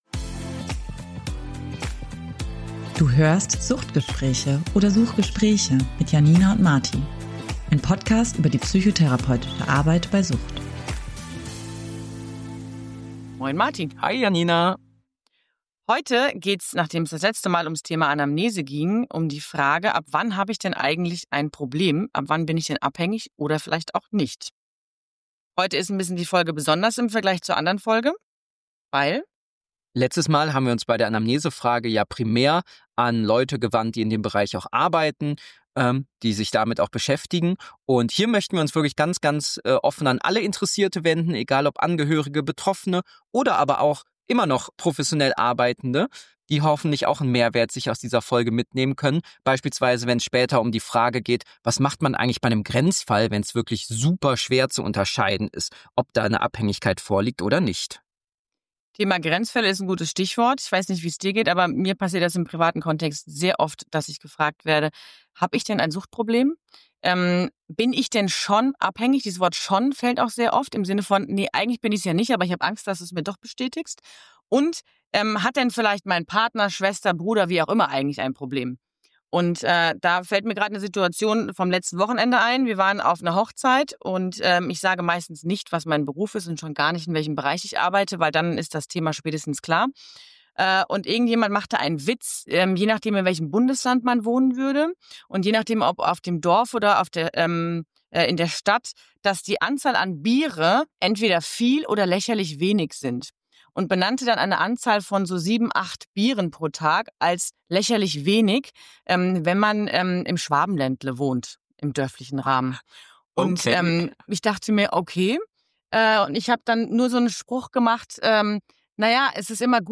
Außerdem in dieser Folge: unsere Best-of-Strategien, falls wir einen diagnostischen Grenzfall vor uns haben, bei dem schwer einzuschätzen ist, ob man bereits von einer Abhängigkeit spricht und auch ein Rollenspiel, bei dem wir das Besprochenen einmal praxisnah umsetzen.